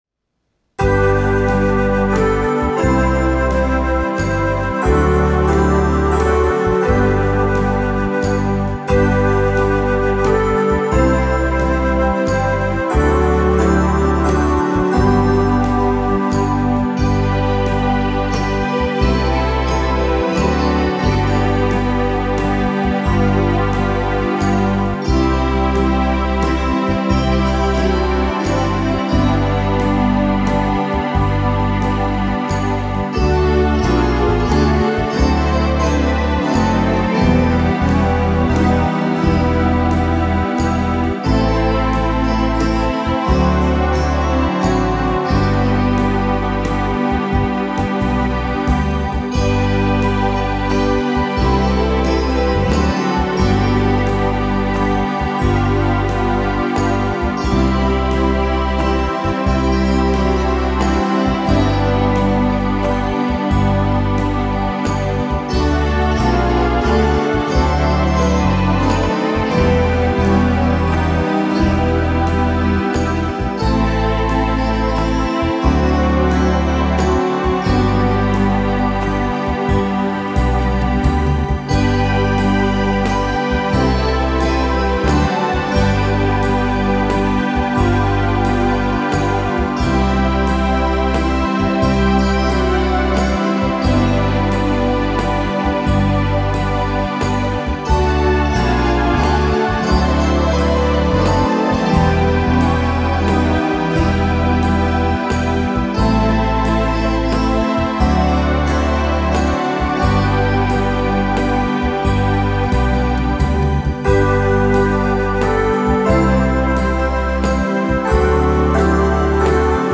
Weihnachtslieder
Leise rieselt der Schnee (instrumental)